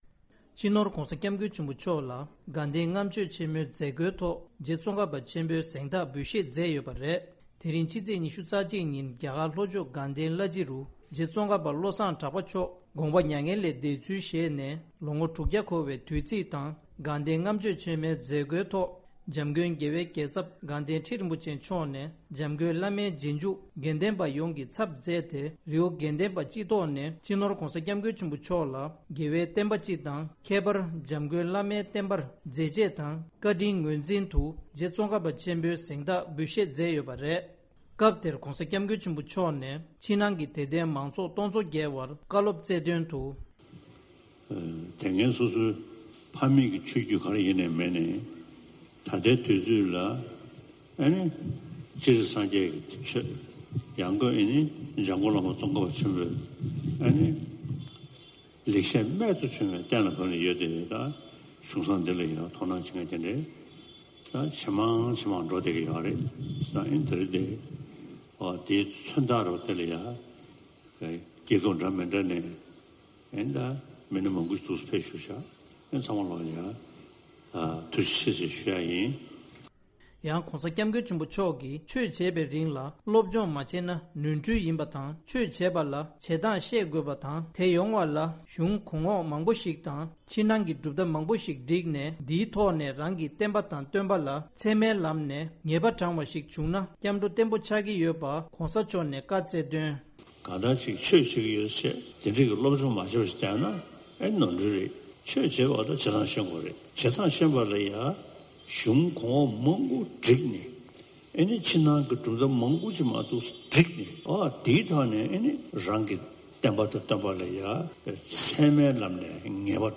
ས་གནས་ནས་བཏང་བའི་གནས་ཚུལ།
སྒྲ་ལྡན་གསར་འགྱུར། སྒྲ་ཕབ་ལེན།